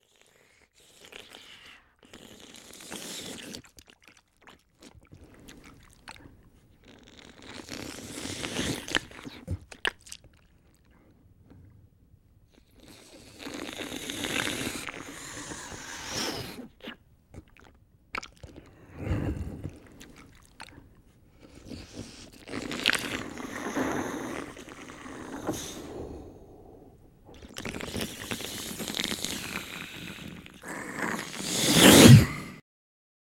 monster-sound